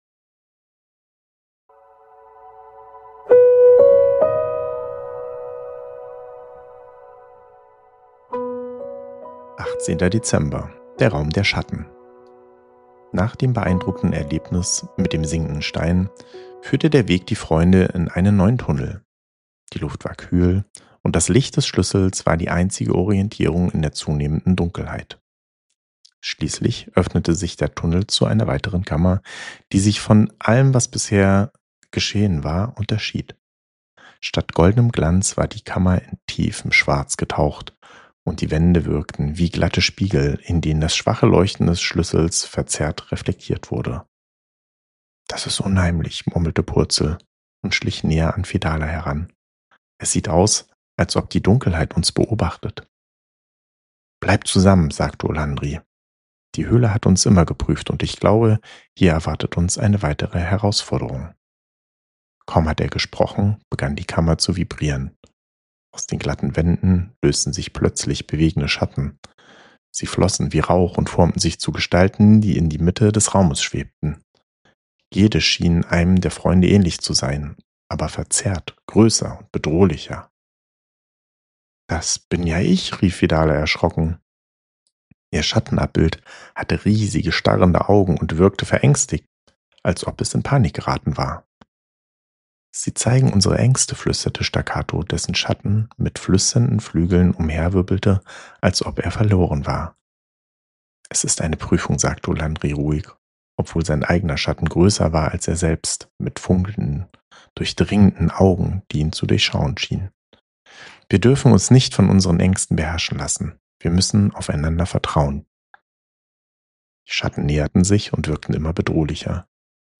Ruhige Adventsgeschichten über Freundschaft, Mut und Zusammenhalt